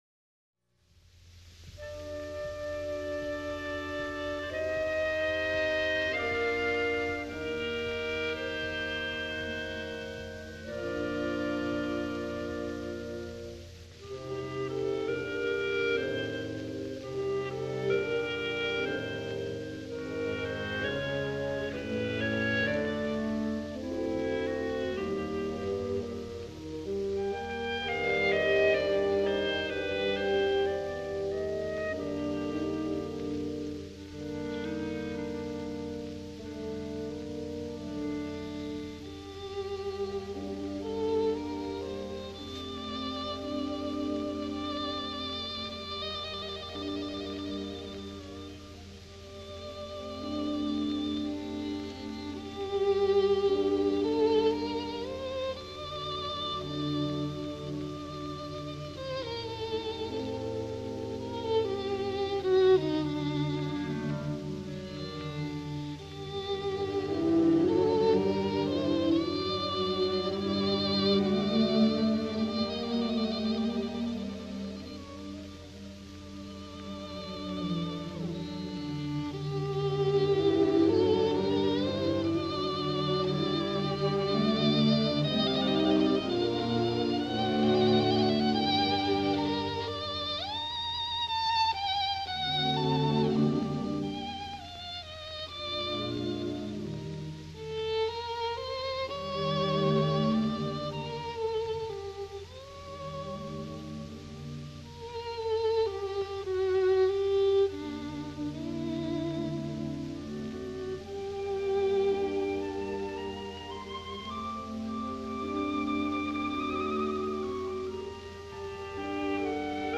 第二乐章,短歌,行板,g小调，3/4拍，三段体
其沉思般的抒情,被称为"小抒情曲".主要旋律真挚动人，略带伤感